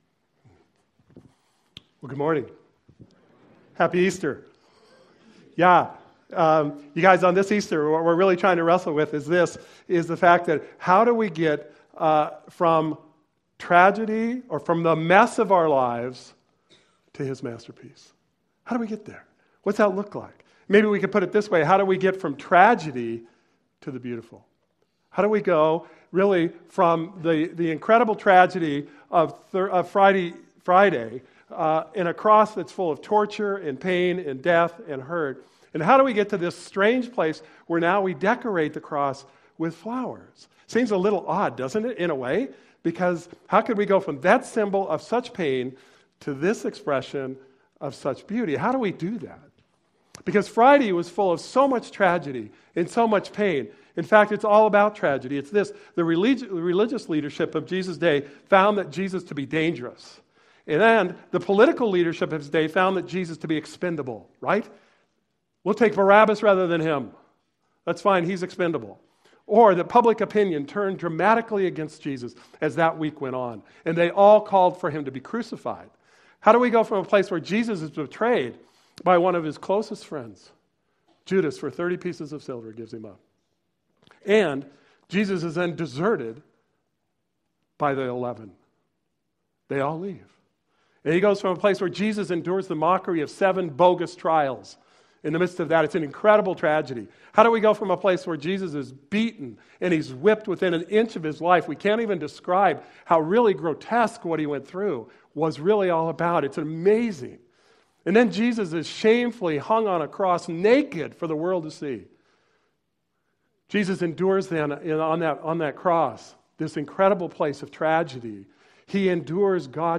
Easter Service – March 27, 2016